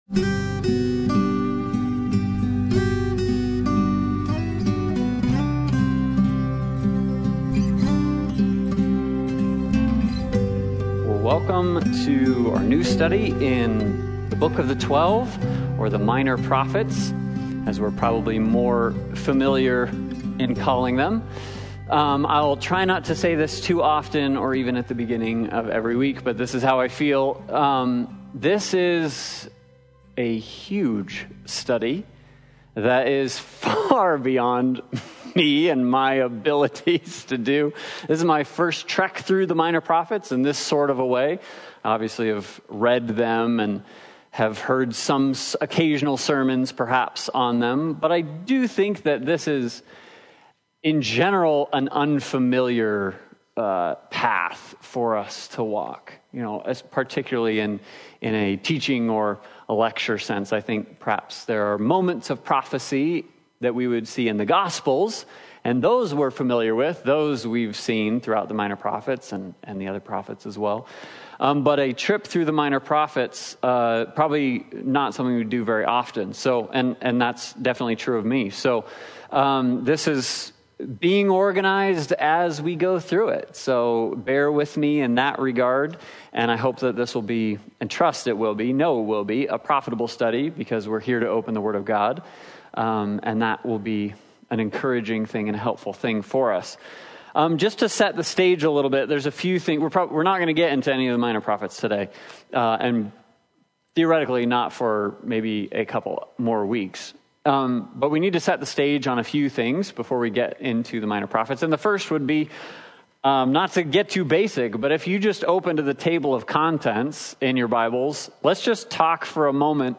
The Book of the Twelve Service Type: Sunday Bible Study « New Earth